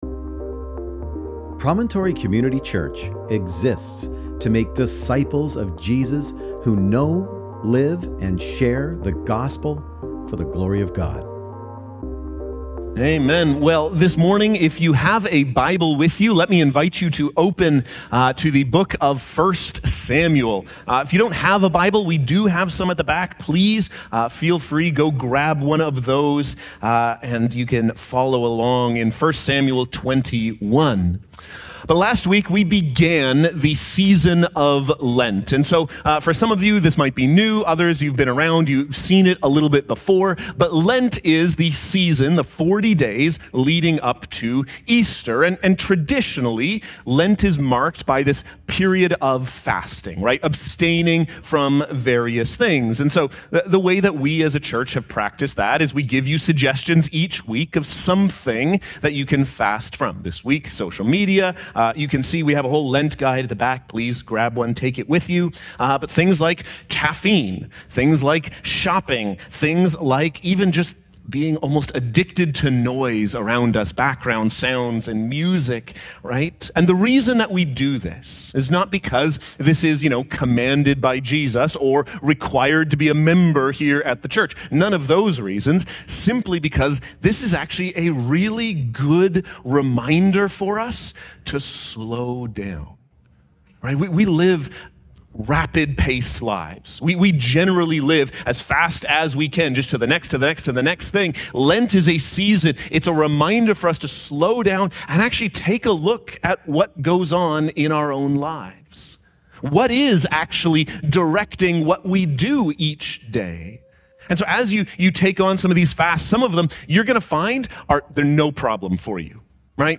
Sermon Transcript: (transcribed with AI) Well, this morning, if you have a Bible with you, let me invite you to open to the book of First Samuel.